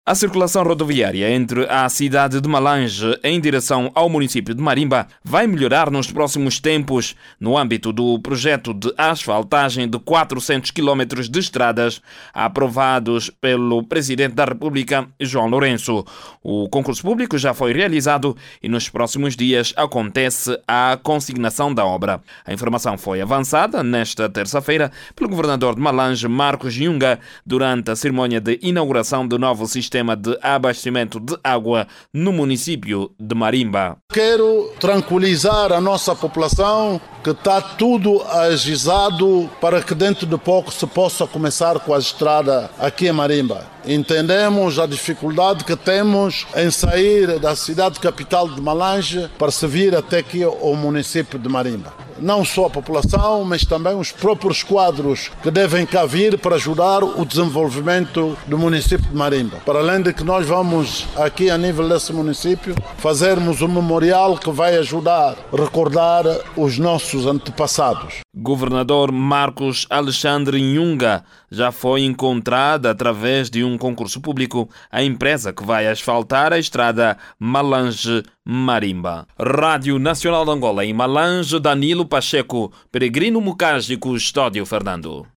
A estrada que liga à cidade de Malanje ao município de Marimba, vai conhecer melhorias dentro de pouco tempo. O dado foi avançado pelo governador de Malanje, Marcos Alexandre Nhunga, no acto de inauguração do novo sistema de abastecimento de água em Marimba.